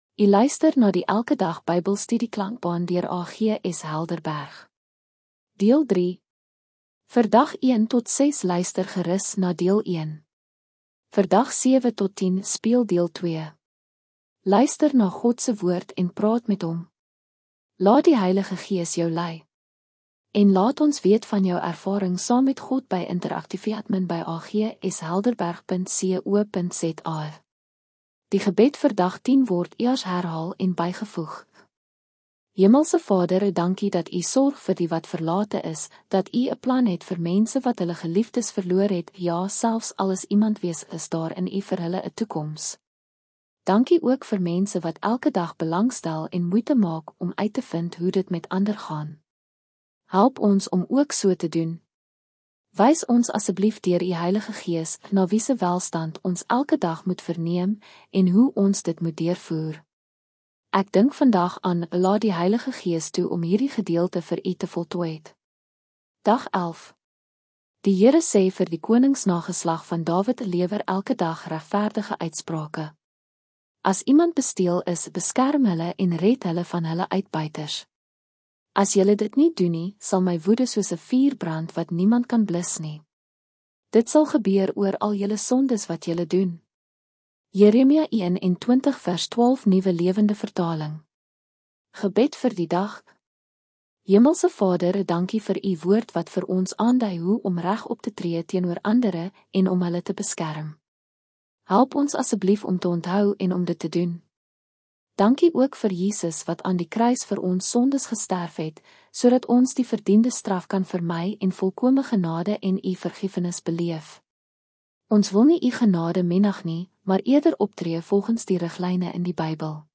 Deel 3 Gratis Klankboek van die Elke Dag Bybel Studie